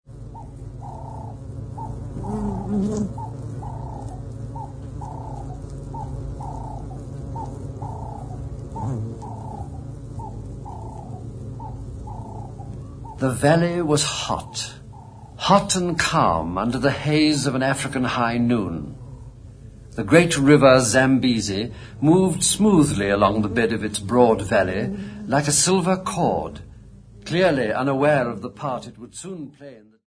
Radio broadcast
Music
A feature programme for broadcasting on the Batonga people of the Zambesi Valley which was submerged beneath the waters of the Kariba Dam, being edited version for the broadcast by the British Broadcasting Corporation
44100Hz 24Bit Stereo
15ips reel